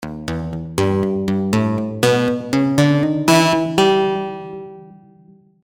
By engaging the key grid, the Velocirapture tool will work in conjunction with the current key signature to produce more musical results.
after locking to the D# Enigmatic key signature